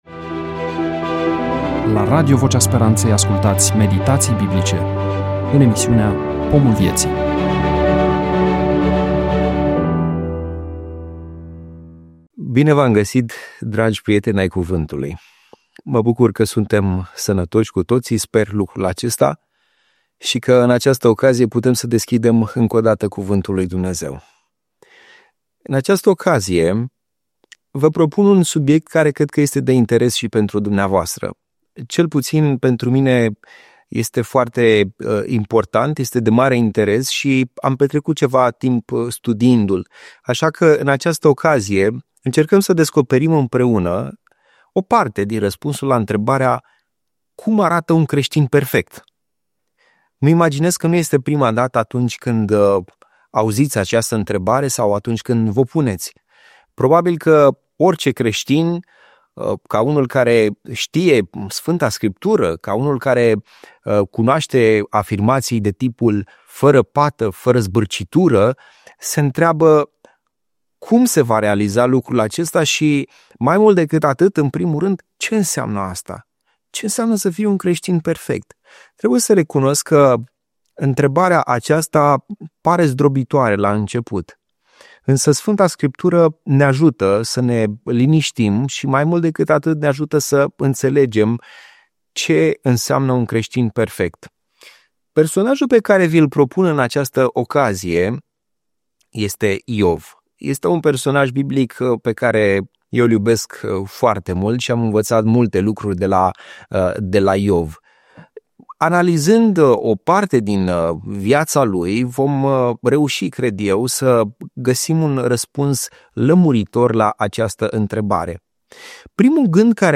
EMISIUNEA: Predică DATA INREGISTRARII: 02.01.2026 VIZUALIZARI: 16